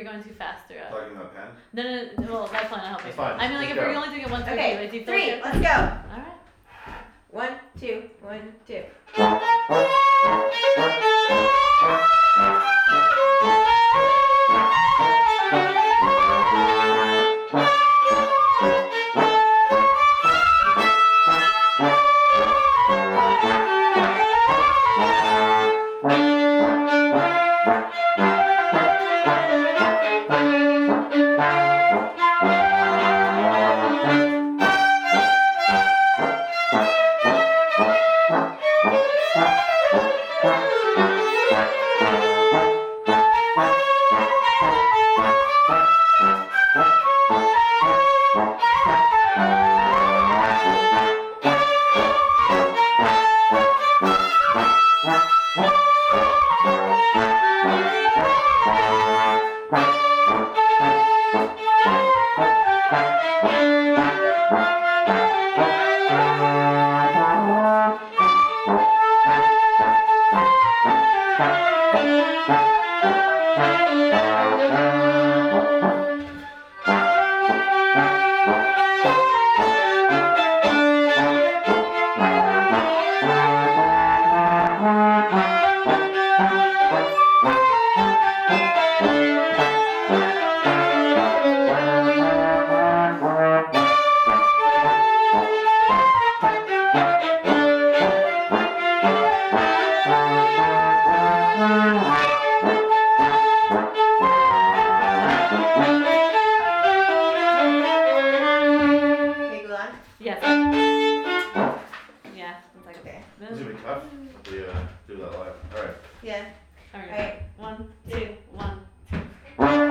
Audio from Kol Tzedek band rehearsals
violin
trombone
flute/sax
November 29 rehearsal